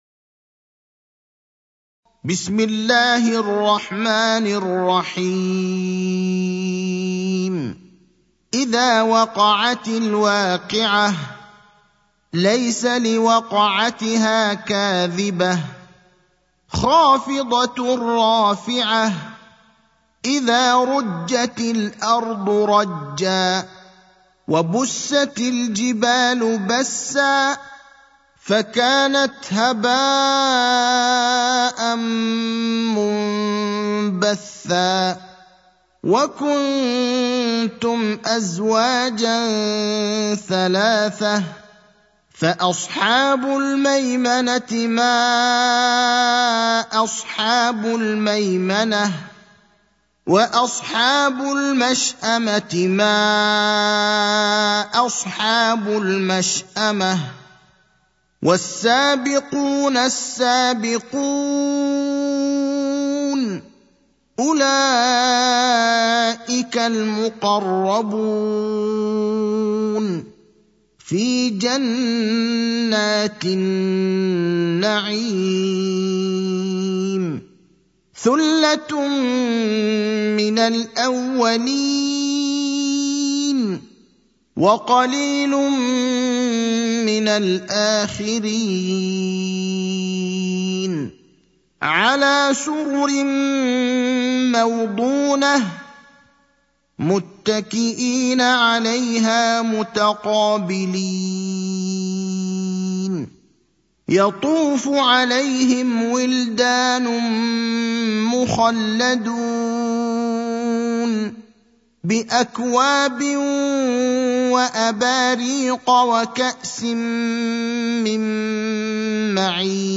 المكان: المسجد النبوي الشيخ: فضيلة الشيخ إبراهيم الأخضر فضيلة الشيخ إبراهيم الأخضر الواقعة (56)